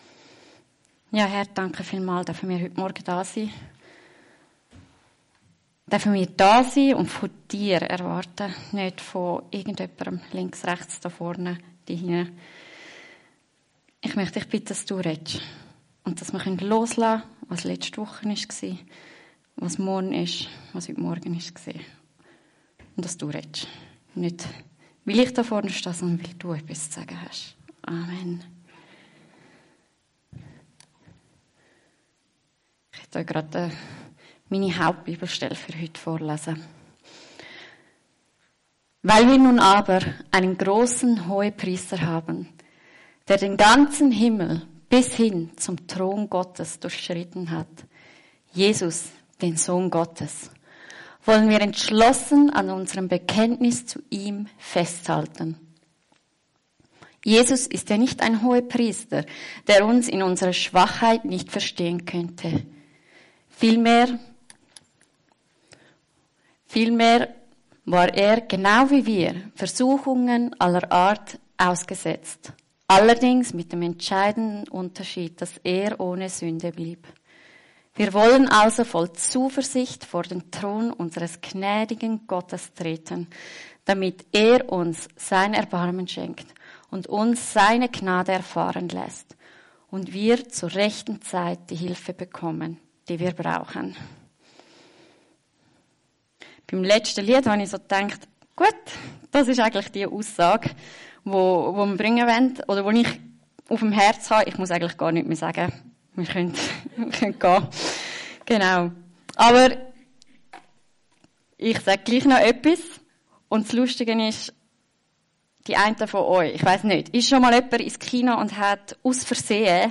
In sechs Predigten tauchen wir in das Buch Josua ein und lassen uns von Gottes Zuspruch an Josua neu herausfordern: Sei mutig und stark!